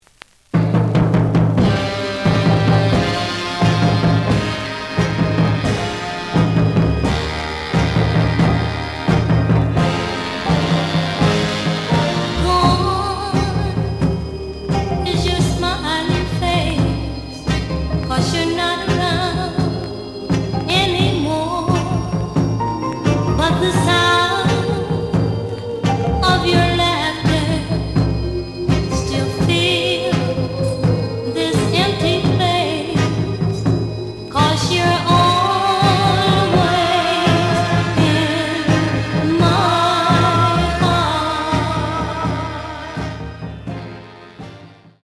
The audio sample is recorded from the actual item.
●Genre: Soul, 60's Soul
Slight affect sound.